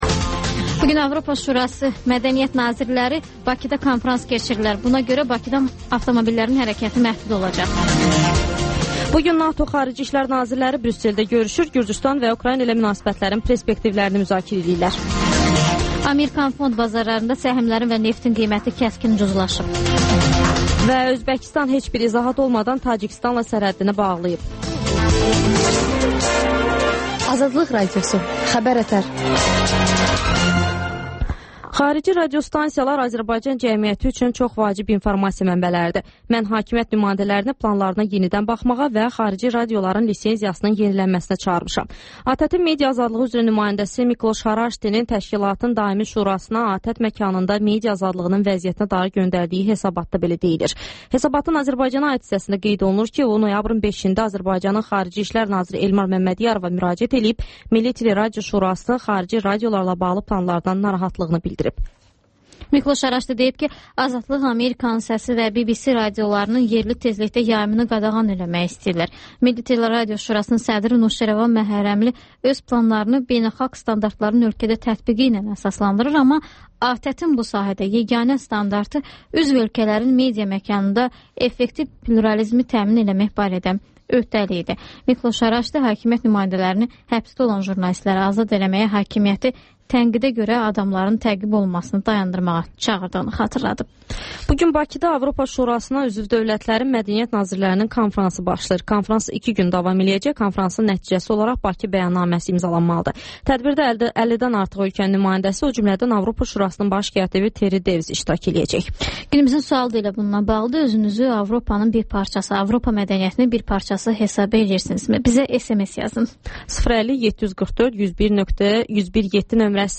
Xəbər-ətər: xəbərlər, müsahibələr, sonra İZ mədəniyyət proqramı